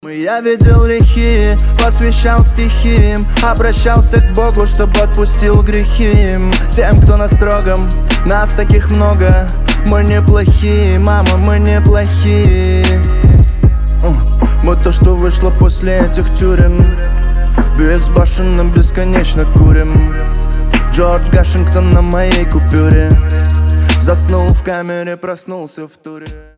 • Качество: 320, Stereo
мужской вокал
душевные
русский рэп